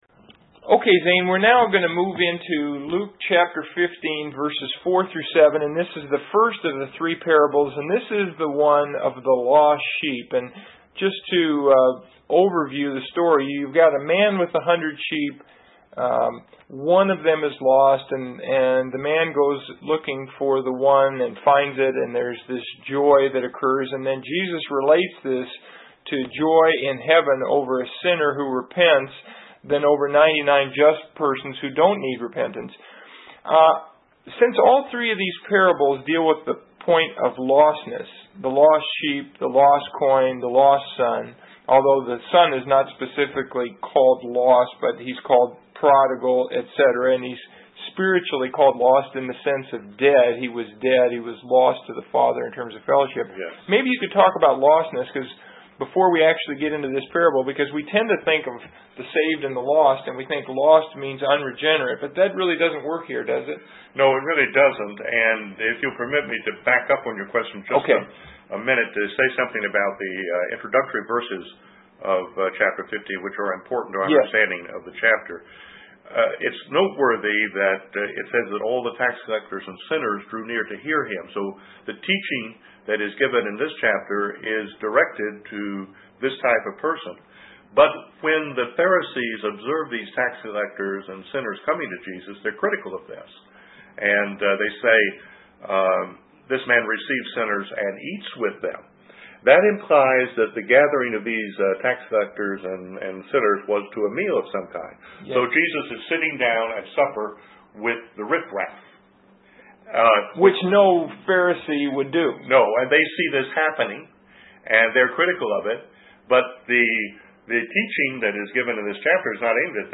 Discussions on Specific Passages